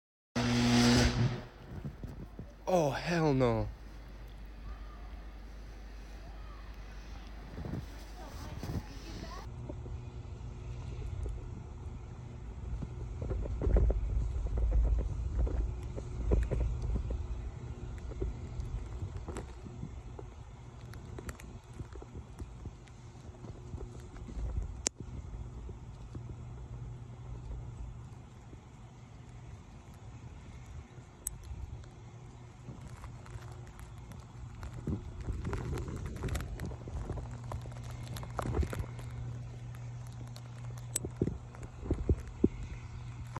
Power lines falling down from sound effects free download
Power lines falling down from heavy snow